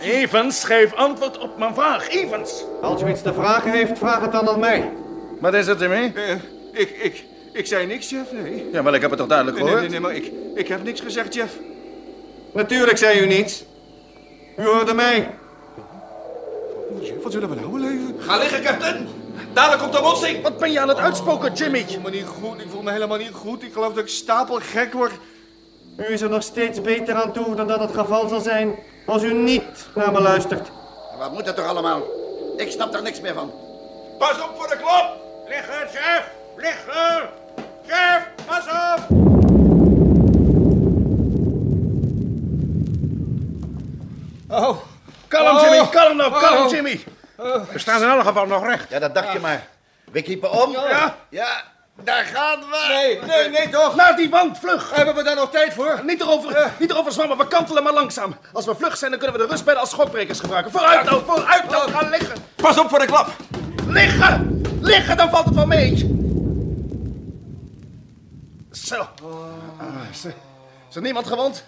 Door de aantrekkingskracht van de grote asteroïde wordt de vrachtvaarder met een zekere kracht naar het oppervlak ervan getrokken. Hoewel niet groots vond ik het opvallende verschil zitten in de geluiden die de BBC gebruikte om die klap weer te geven, tegenover de geluiden die men bij de KRO gebruikte.